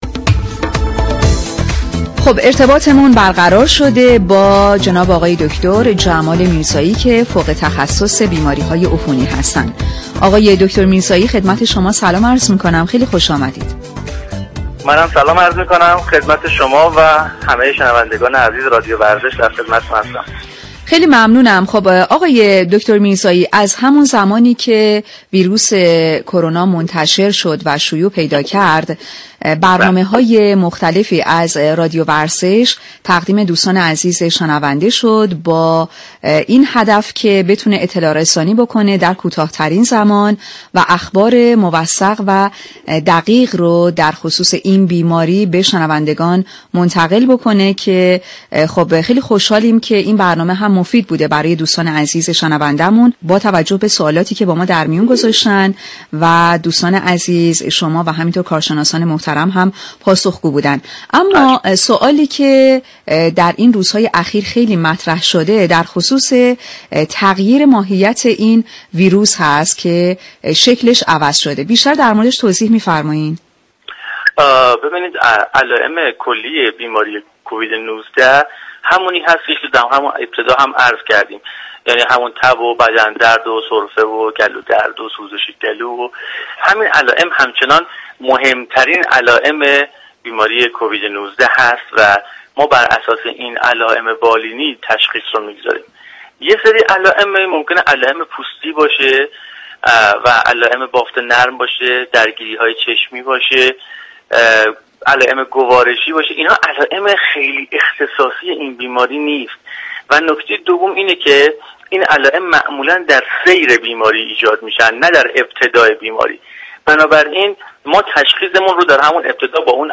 شما می توانید از طریق فایل صوتی پیوست شنونده ادامه صحبت های این متخصص بیماریهای عفونی در برنامه سلامت باشیم رادیو ورزش باشید. برنامه سلامت باشیم با محوریت آگاهی رسانی در خصوص بیماری كرونا هر روز ساعت 8:30 به مدت 30 دقیقه از شبكه رادیویی ورزش تقدیم شنوندگان می شود.